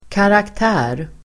Uttal: [karakt'ä:r]